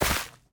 Minecraft Version Minecraft Version snapshot Latest Release | Latest Snapshot snapshot / assets / minecraft / sounds / block / rooted_dirt / step3.ogg Compare With Compare With Latest Release | Latest Snapshot
step3.ogg